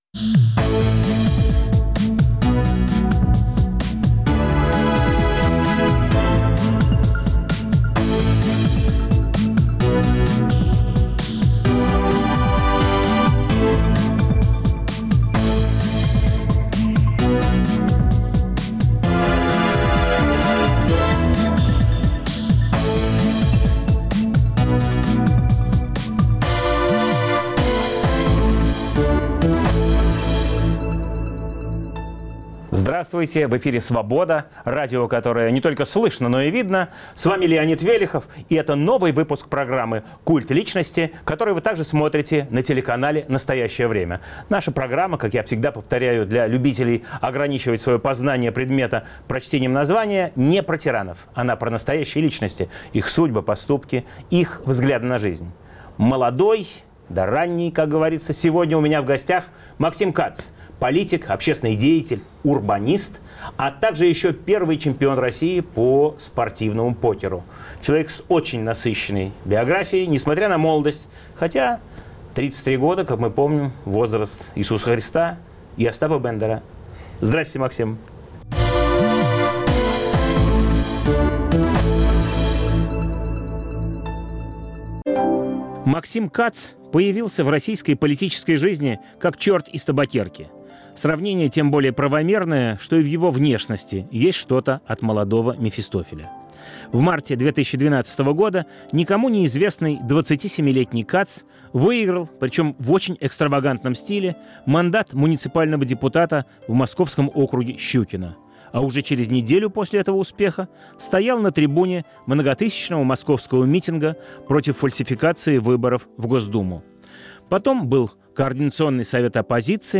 В студии "Культа личности" Максим Кац - молодой политик-оппозиционер, общественный деятель.